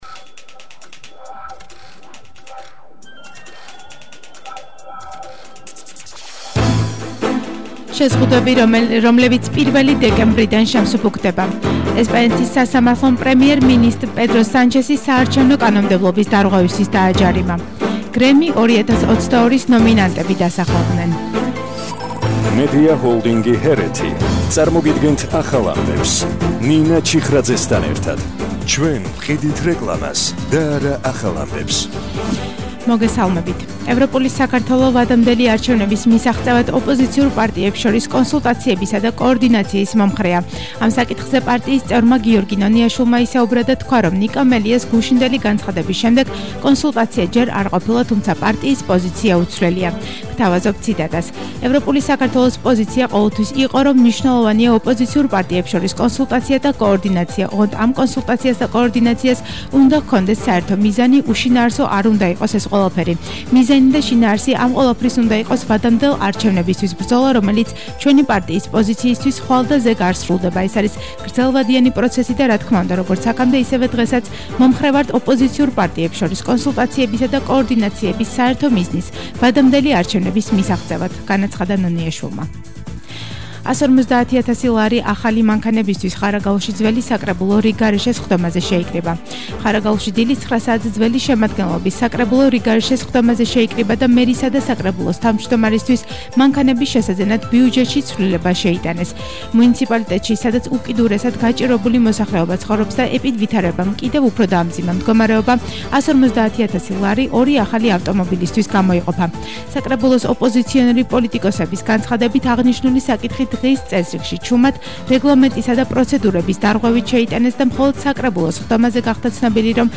ახალი ამბები 15:00 საათზე –24/11/21 - HeretiFM